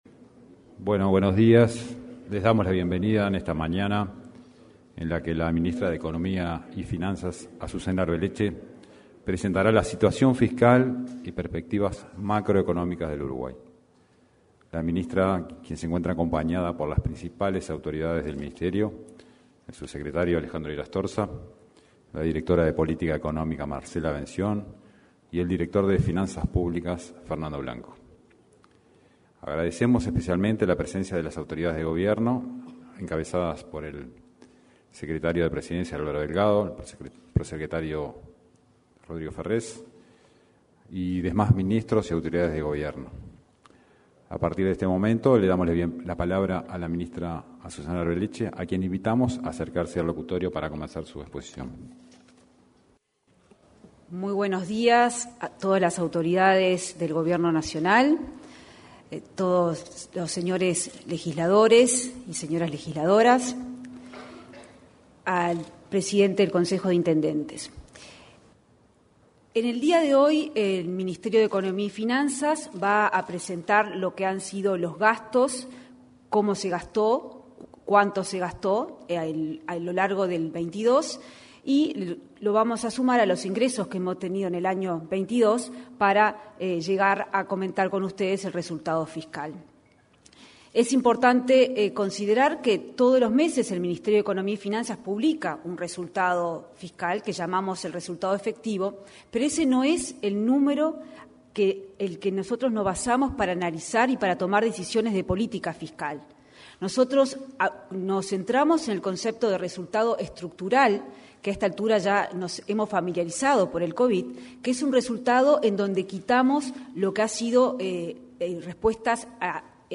Presentación del Informe sobre Situación y Perspectivas Macroeconómicas del Uruguay 15/02/2023 Compartir Facebook X Copiar enlace WhatsApp LinkedIn Este miércoles 15, la ministra de Economía y Finanzas, Azucena Arbeleche, presentó el Informe sobre Situación y Perspectivas Macroeconómicas del Uruguay. Luego, en un intercambio con la prensa, intervinieron también el subsecretario de la citada cartera, Alejandro Irastorza, y la directora de Política Económica, Marcela Bensión.